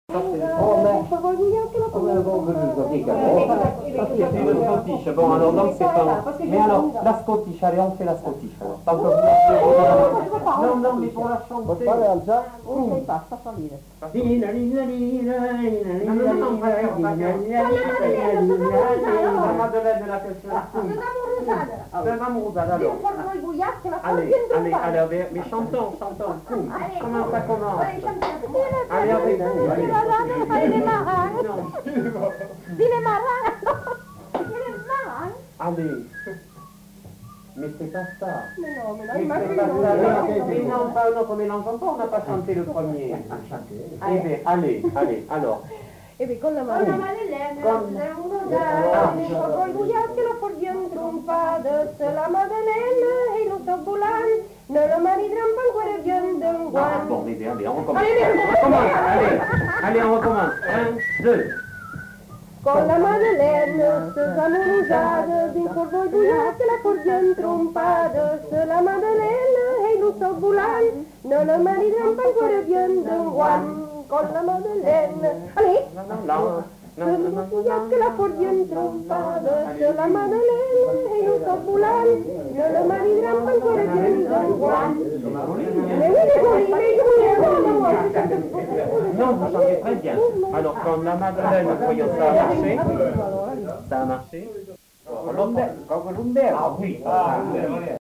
Aire culturelle : Grandes-Landes
Lieu : Luxey
Genre : chant
Effectif : 2
Type de voix : voix de femme
Production du son : chanté
Danse : scottish